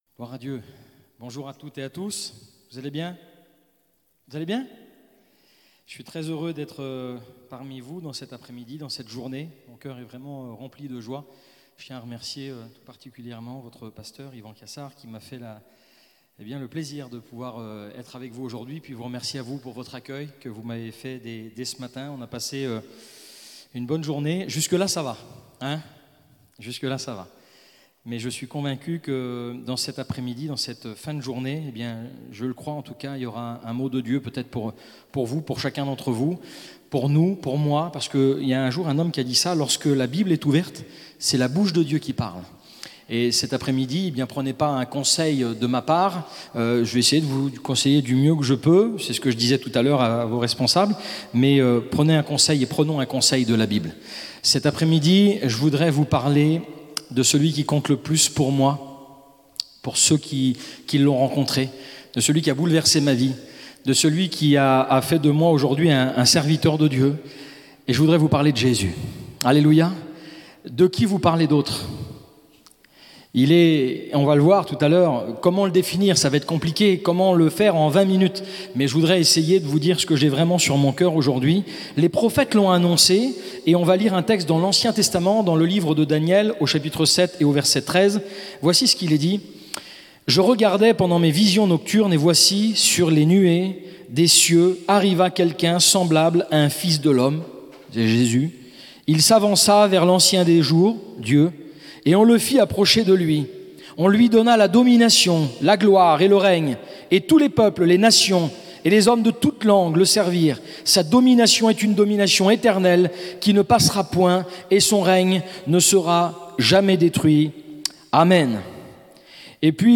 Découvrez en replay vidéo le message apporté à l'Eglise Ciel Ouvert